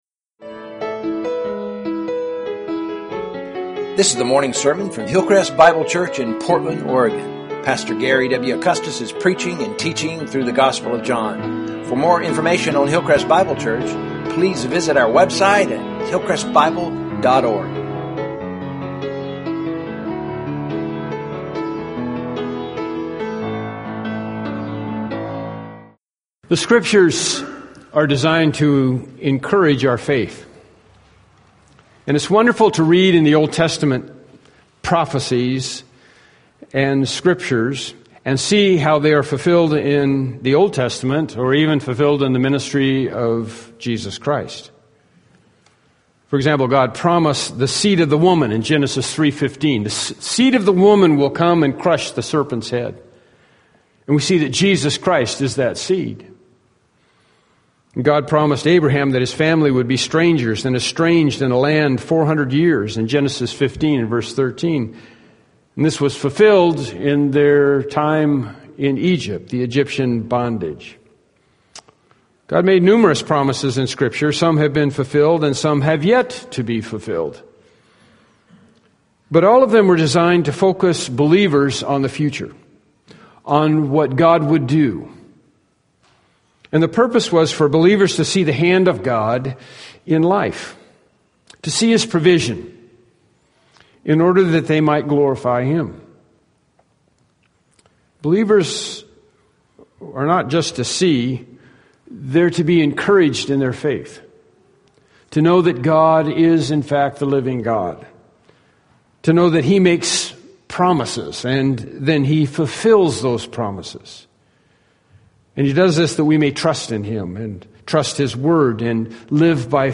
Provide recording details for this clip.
John 13:18-20 Service Type: Morning Worship Service « “Prayer and Assurance” “By Prayer Alone” »